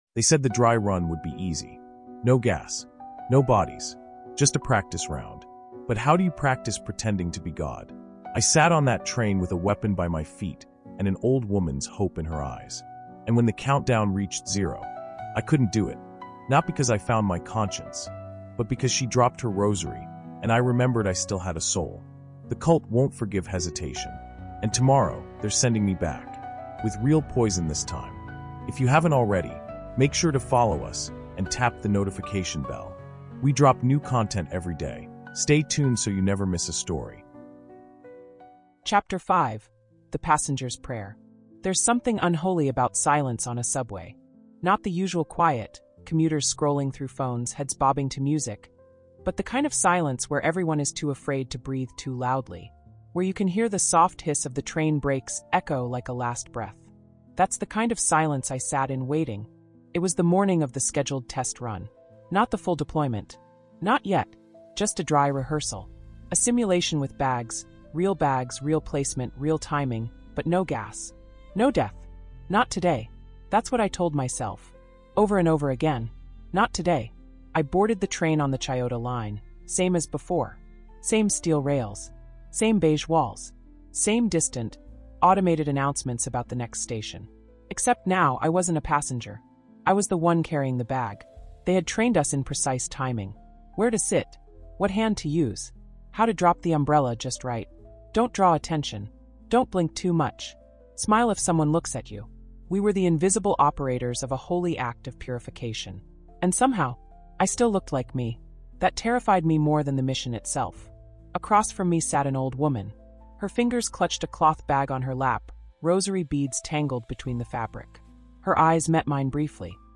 Terror in Tokyo Subway Chapter Five | The Passenger’s Prayer | Audiobook